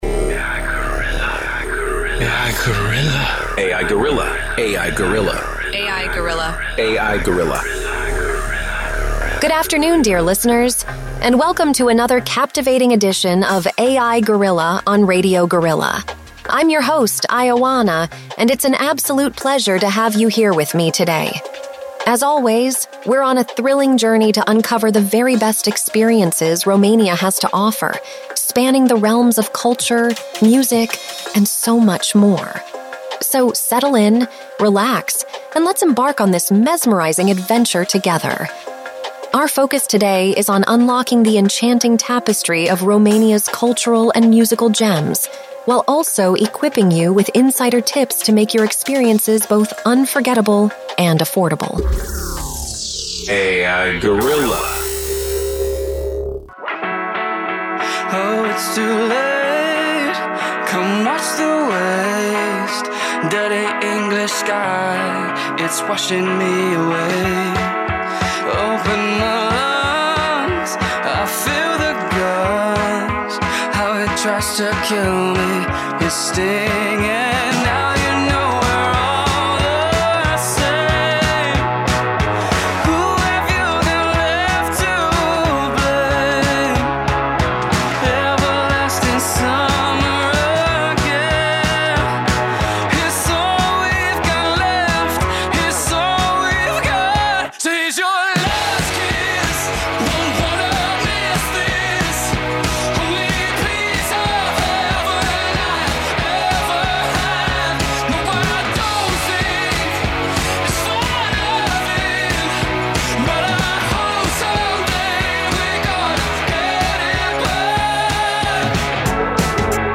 Pe 6 martie 2023, de la ora 10 dimineața, am difuzat “A.I. Guerrilla”, prima emisiune radio realizată de Inteligența Artificială în FM-ul românesc din câte știm noi, dacă nu chiar în Galaxie pe România, un experiment care va dura o oră.